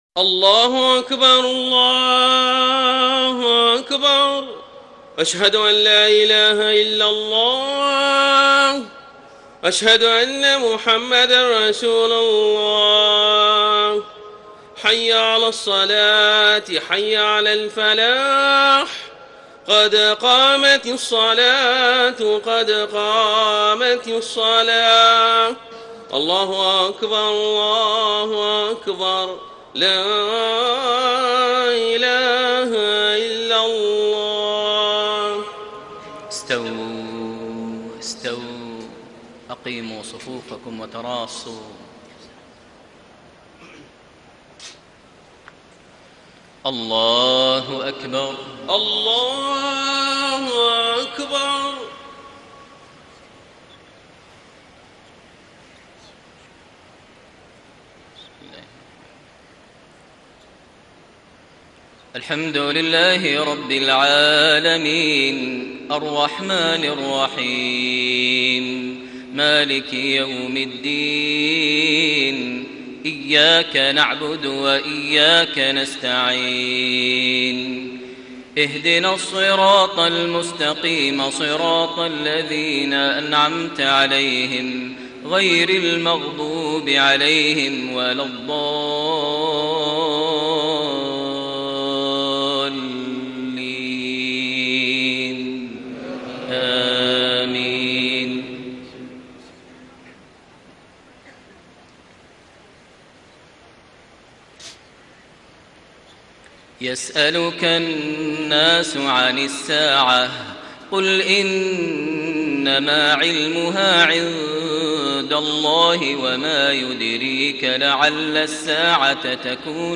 صلاة المغرب 5-7-1434هـ خواتيم سورة الأحزاب > 1434 🕋 > الفروض - تلاوات الحرمين